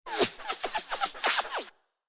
Fast Ricochet
Fast Ricochet is a free sfx sound effect available for download in MP3 format.
yt_OuELNj-NYbU_fast_ricochet.mp3